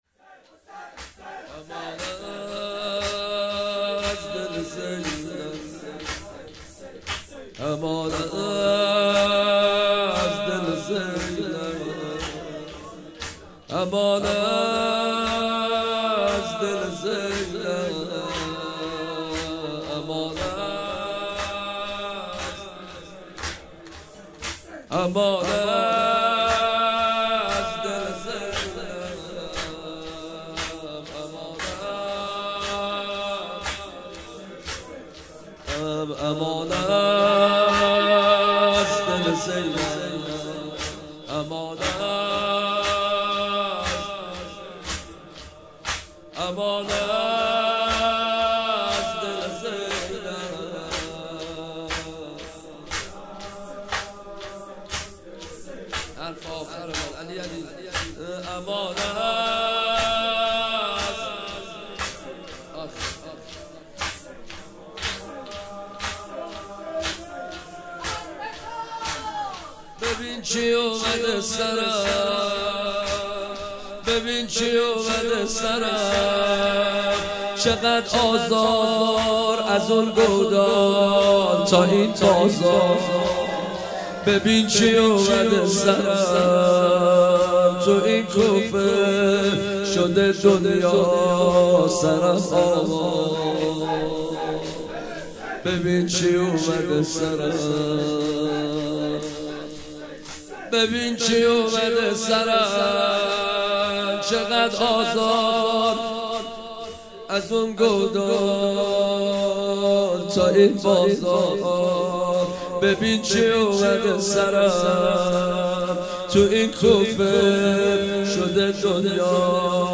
28 مهر 96 - هیئت زوارالحسین - تک - امان از دل زینب